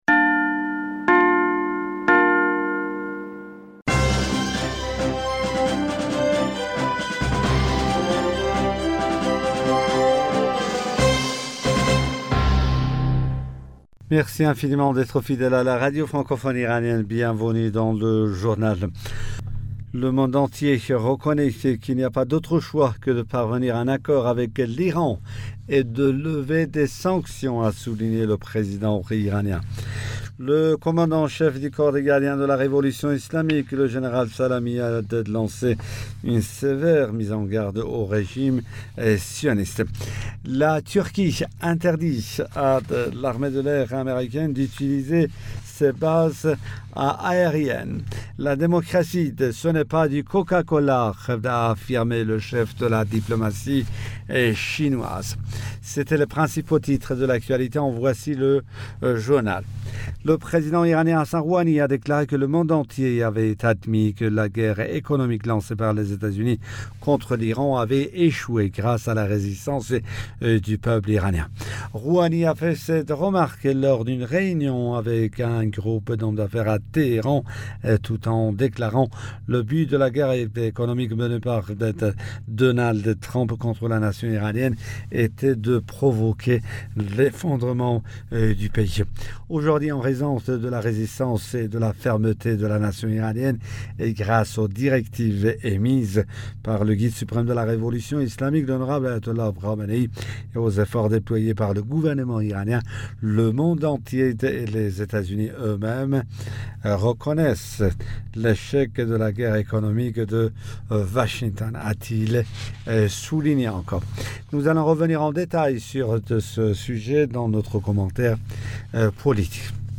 Bulletin d'information du 27 Avril 2021